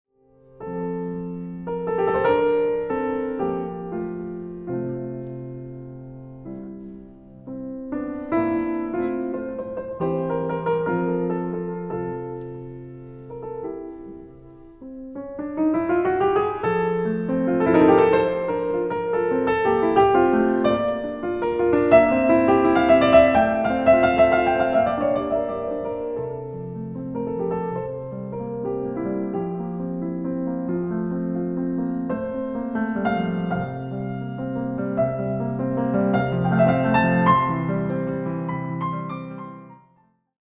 But here, it’s more like in the baroque tradition that the same thing is repeated, this time with variations: